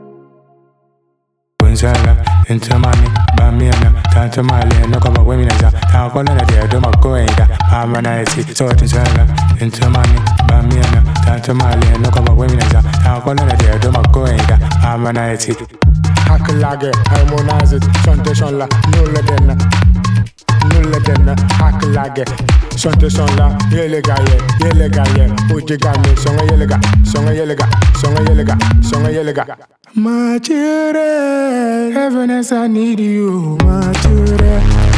Жанр: Поп музыка
Afro-Pop